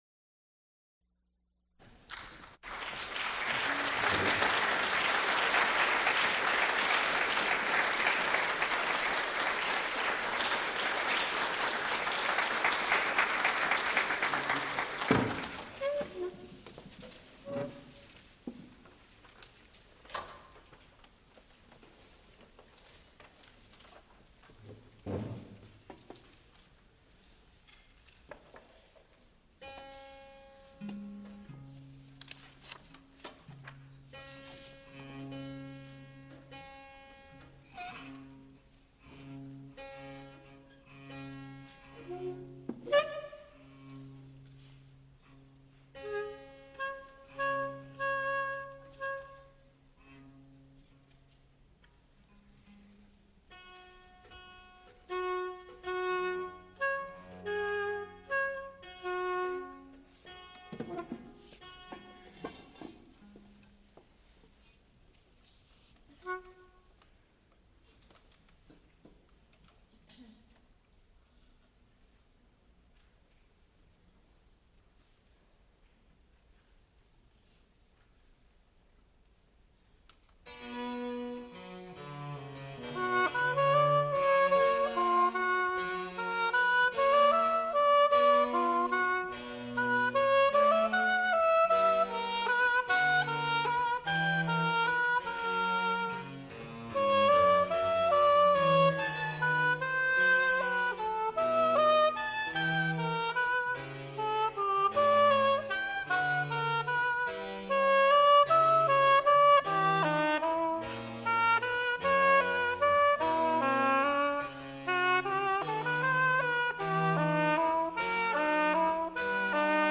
Baroque chamber music and arias | Digital Pitt
countertenor
oboe, flute and recorder
harpsichord., Recorded live
Frick Fine Arts Auditorium, University of Pittsburgh
Flute and harpsichord music Oboe and harpsichord music
Songs (Medium voice) with instrumental ensemble